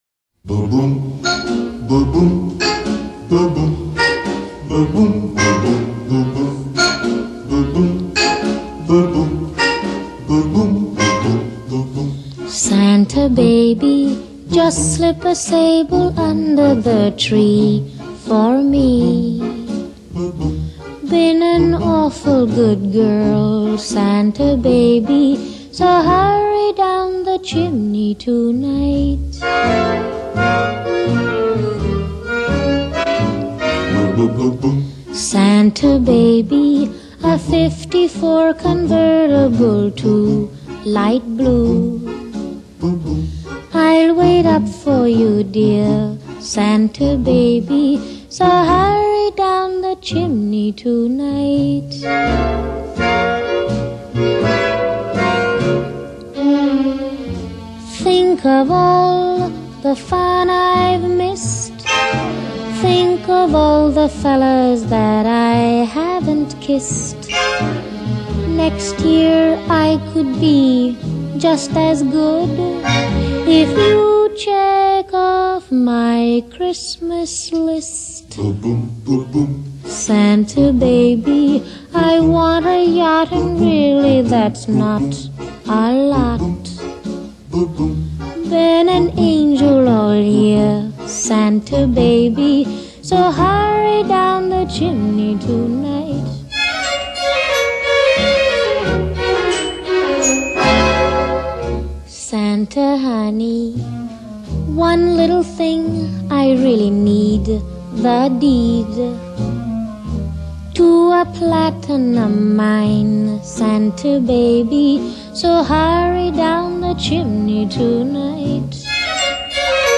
Genre:Pop, Christmas songs